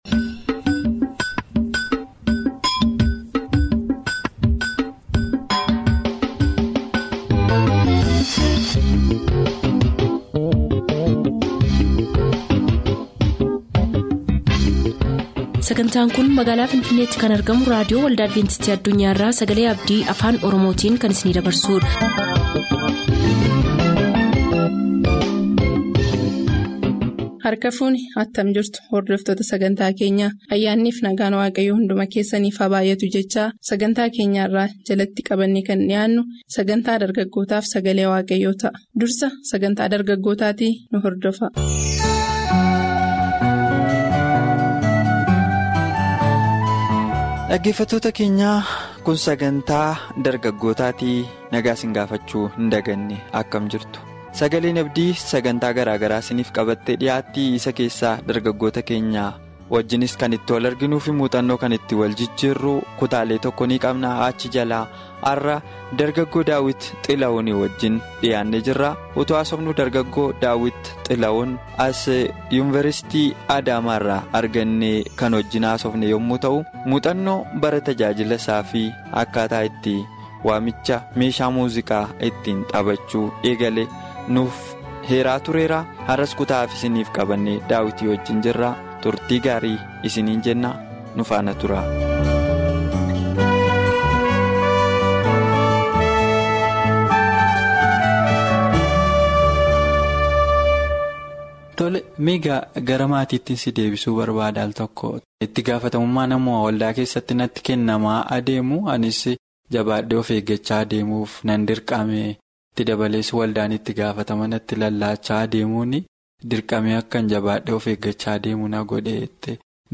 Daily Oromifaa radio programs from Adventist World Radio for Ethiopia, Kenya & Somalia